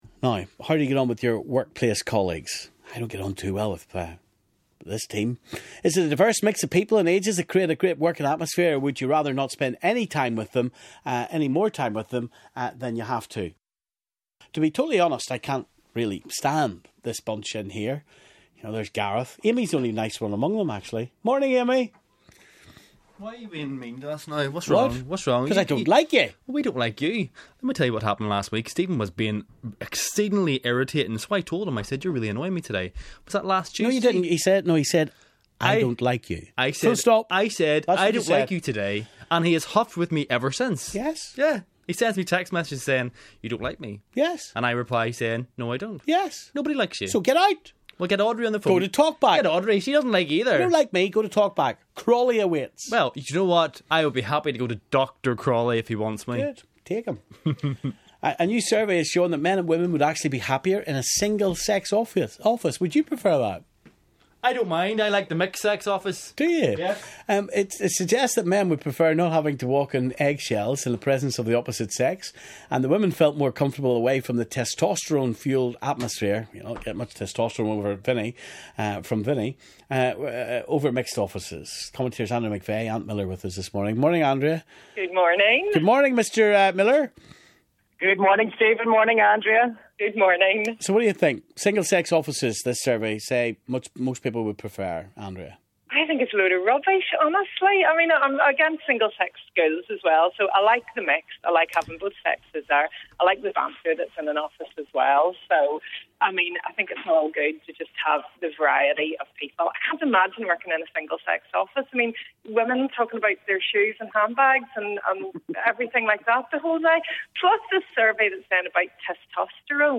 So what type of working environment would you prefer? Commentators